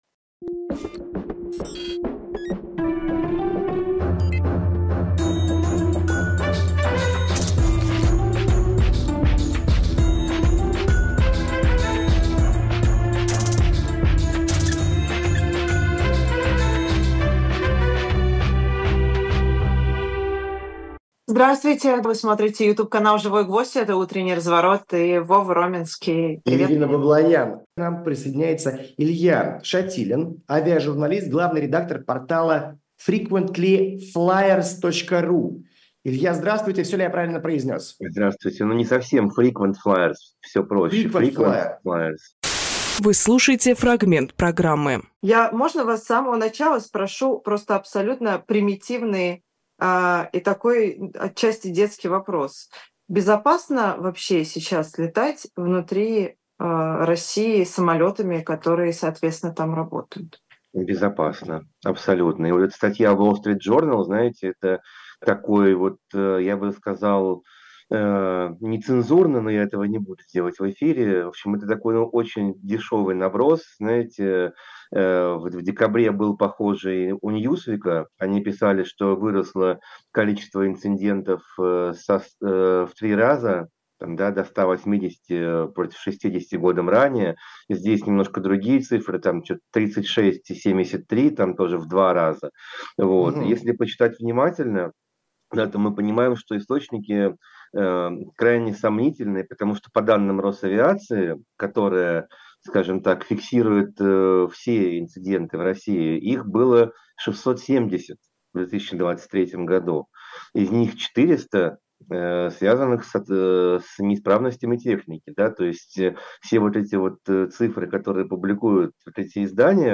Фрагмент эфира от 05.02.24